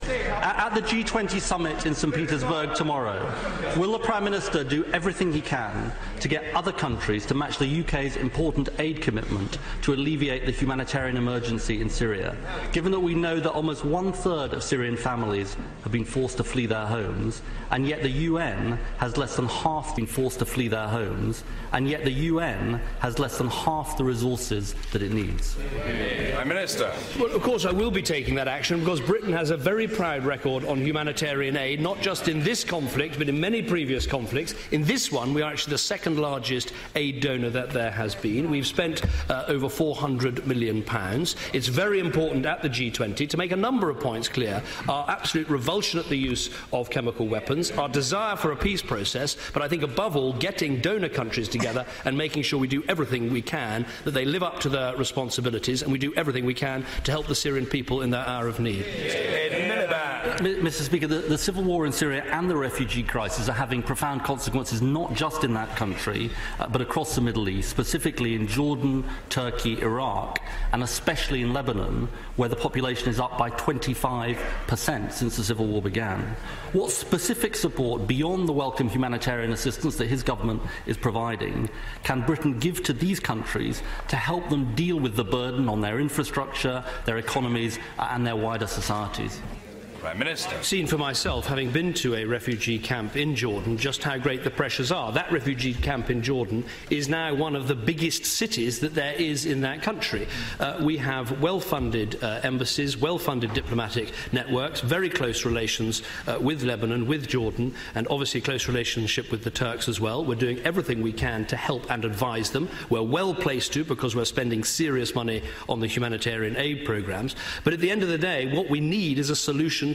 Miliband questions Cameron on Syria at PMQs